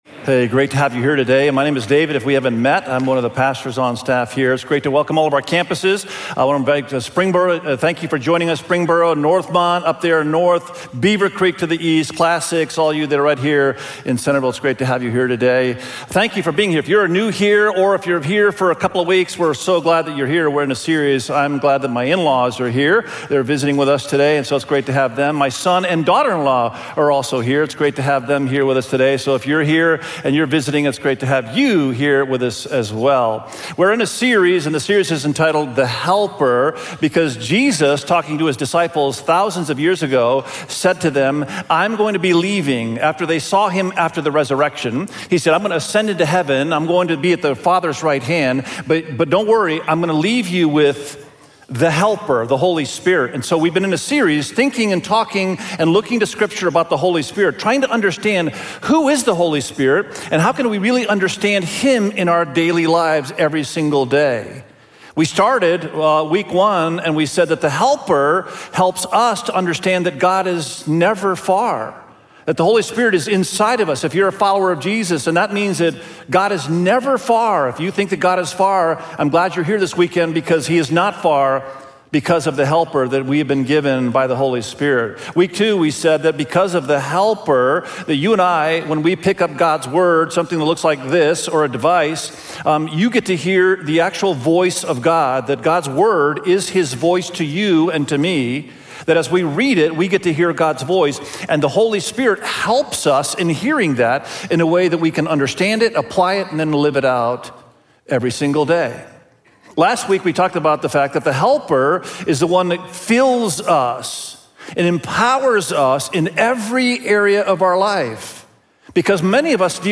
The-Power-of-Your-Spiritual-Gifts_SERMON.mp3